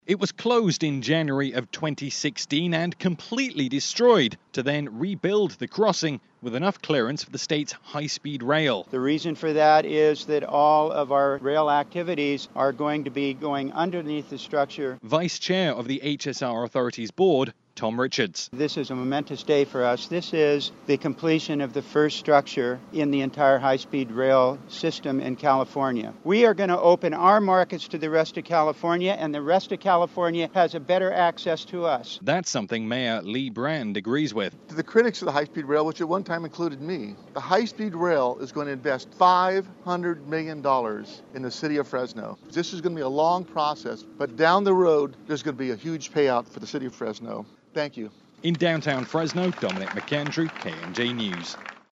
as it aired